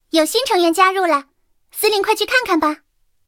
三号建造完成提醒语音.OGG